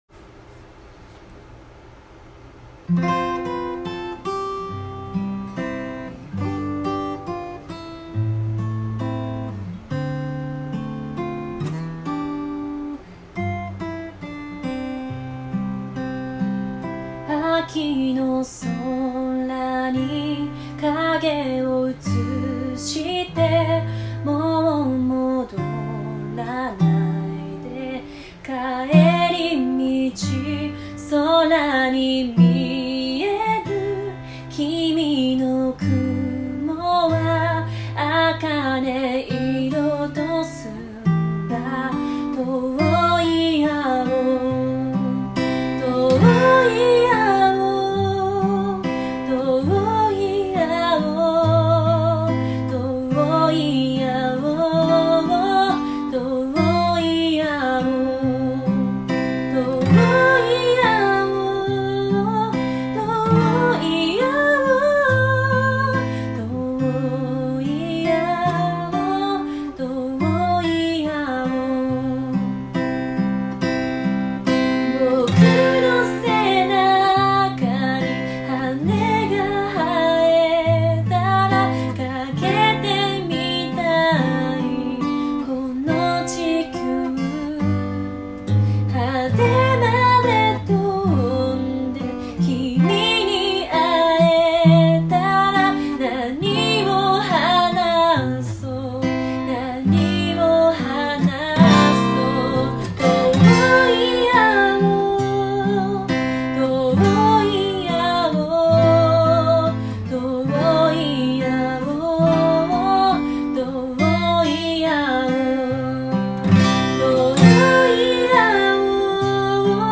カントリースローテンポ穏やか